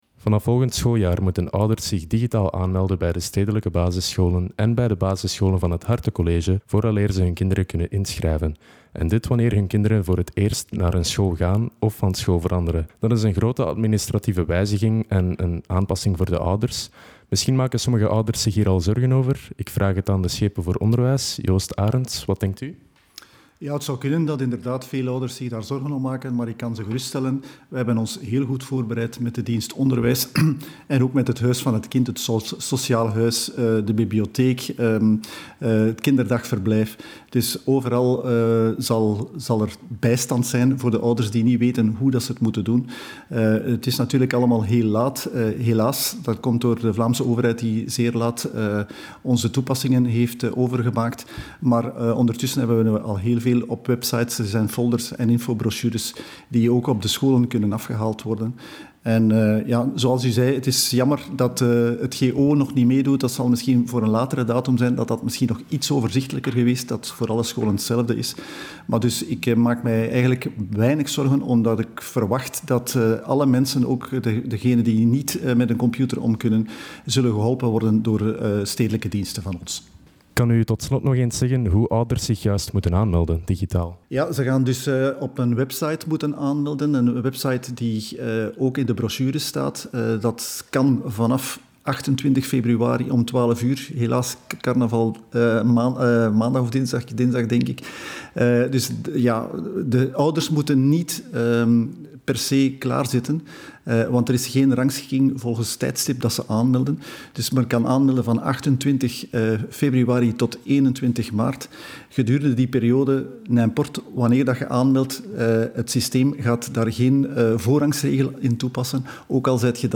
Beluister hieronder het volledige interview met schepen Joost Arents.
interview-Joost-Arents_volledig.mp3